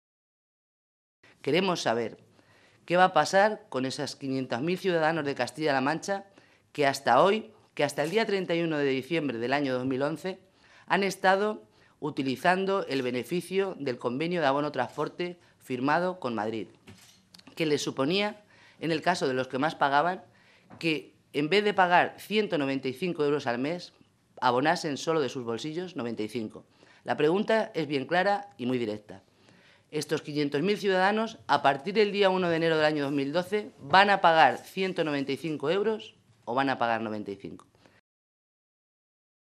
Rosa Melchor, diputada regional del PSOE de Castilla-La Mancha
Cortes de audio de la rueda de prensa